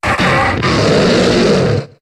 Cri de Trioxhydre dans Pokémon HOME.